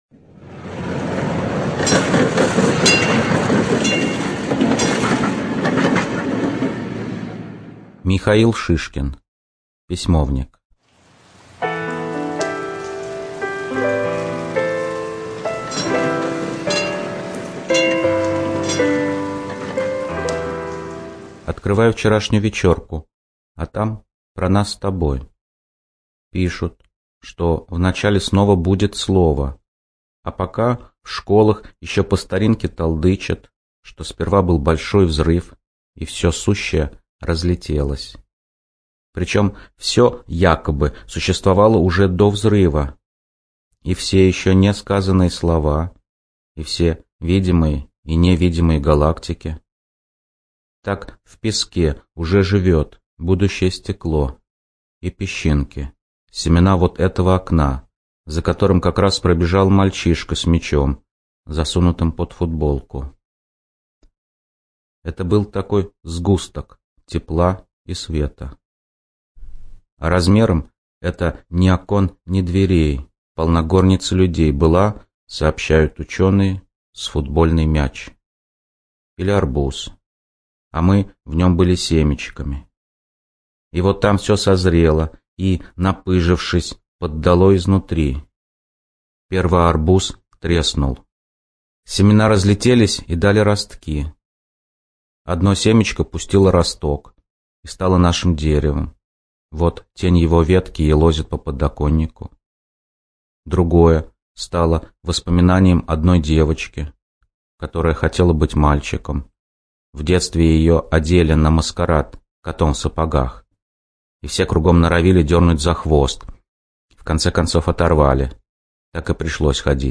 ЧитаетАвтор
Шишкин М - Письмовник (Автор)(preview).mp3